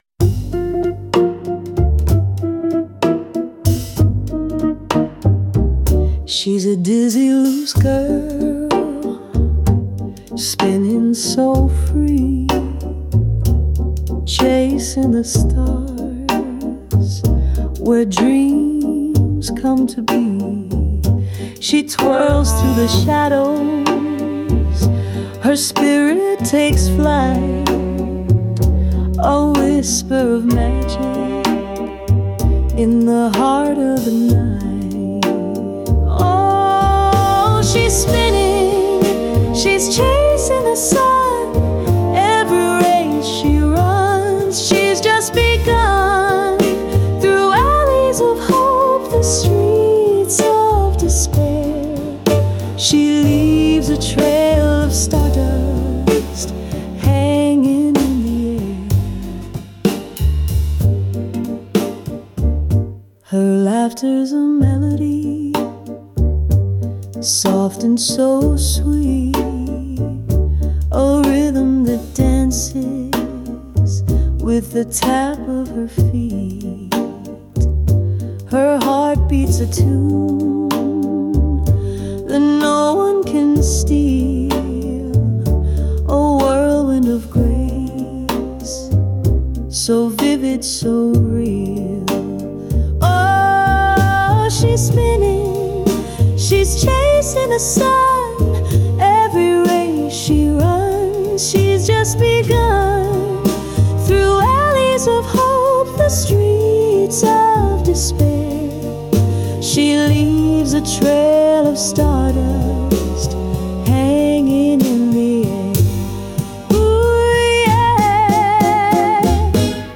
female vocals, slow swing, jazz